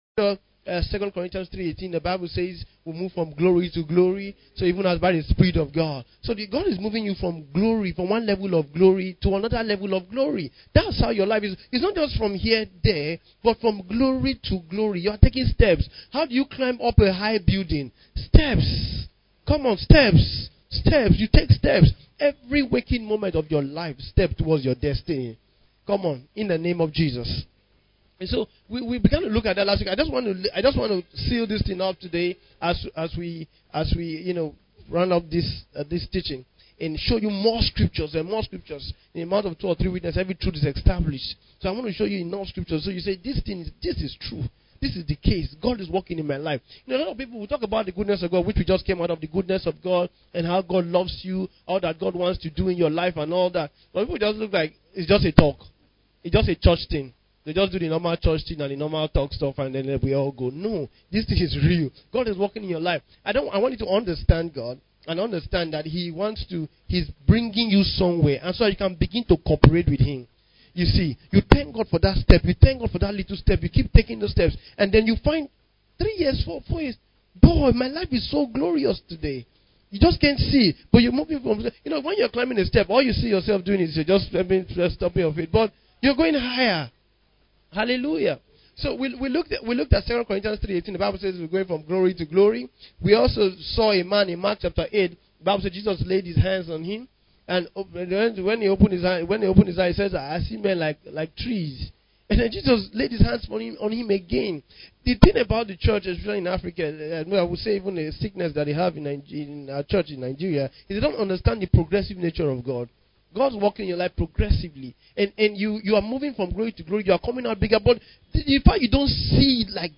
In this final part of the teaching